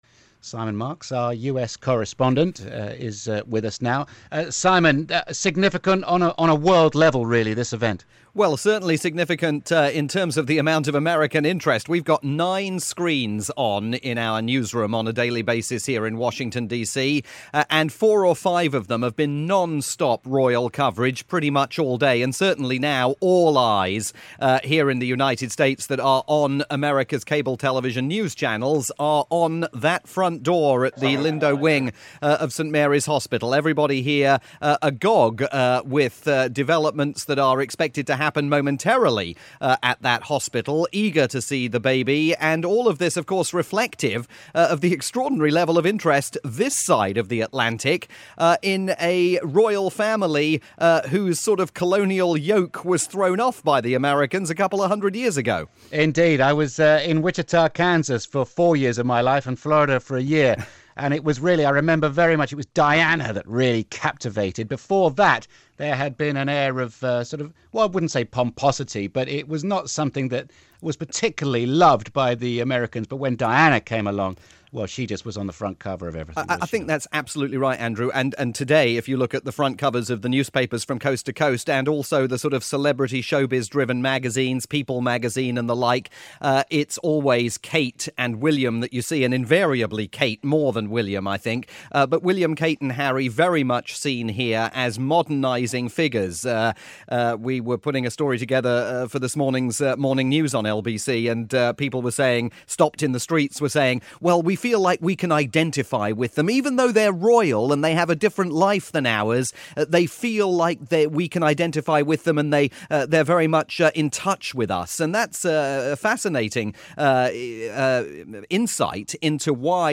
Live coverage of the Prince of Cambridge's first appearance in public, as broadcast by London's LBC 97.3FM.